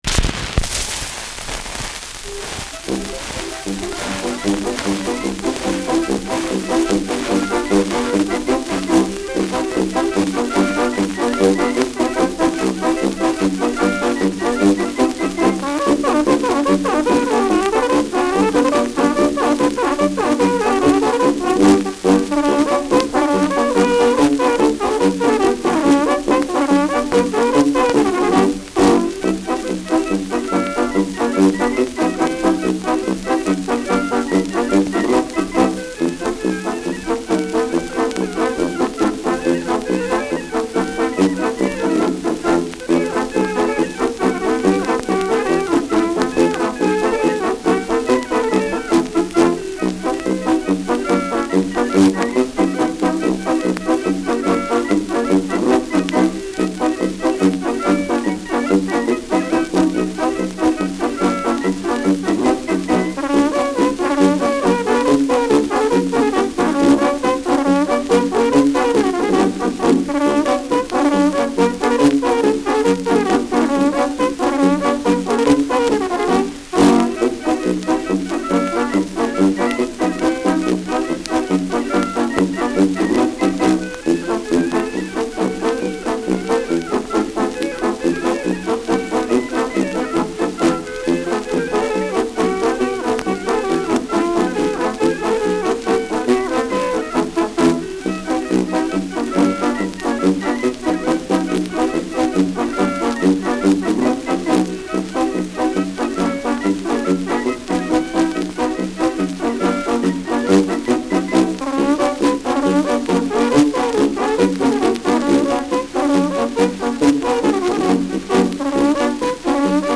Schellackplatte
Abgespielt : Anfang leise : Gelegentlich leichtes Knacken und leichte Verzerrung
Dachauer Bauernkapelle (Interpretation)